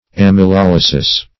Search Result for " amylolysis" : Wordnet 3.0 NOUN (1) 1. conversion of starch to sugar ; The Collaborative International Dictionary of English v.0.48: amylolysis \am`y*lol"y*sis\ ([a^]m`[i^]*l[o^]l"[i^]*s[i^]s), n. [Amylum + Gr. ly`sis a loosing.]
amylolysis.mp3